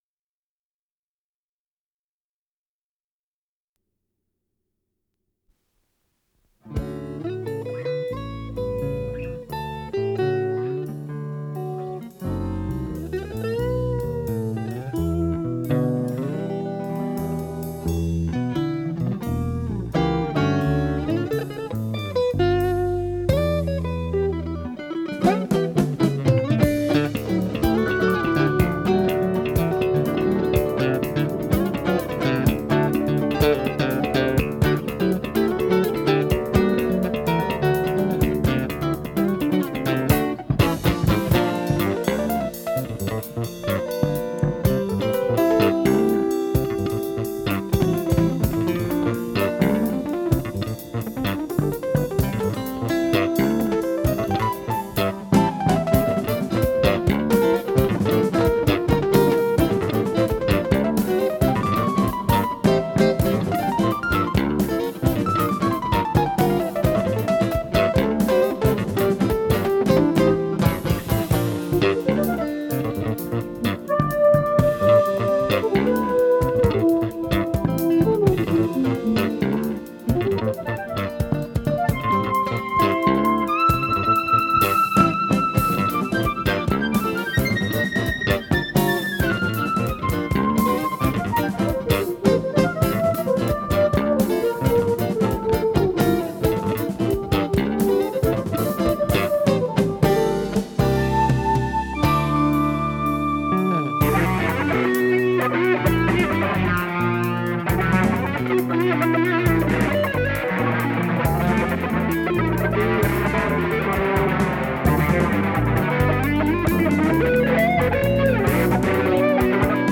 с профессиональной магнитной ленты
ПодзаголовокПодвижно
Скорость ленты38 см/с